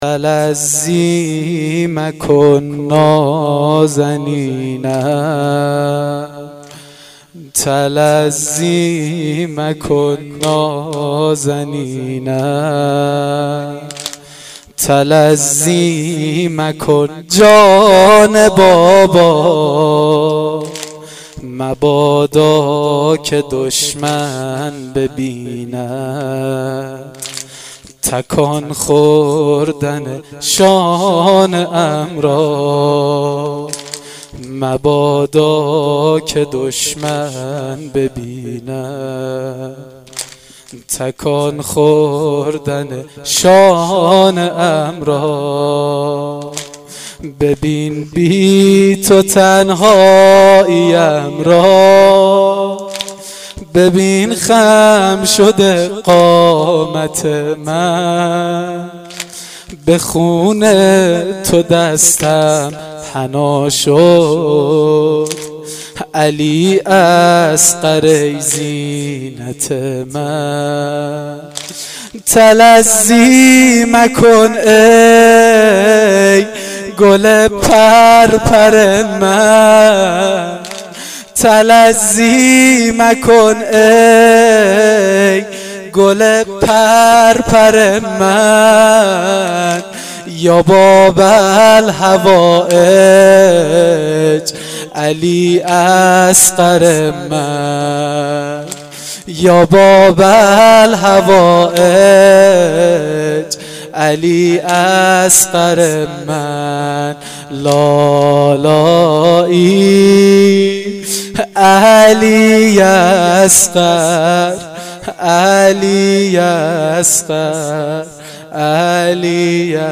واحد سنگین شب هفتم محرم1393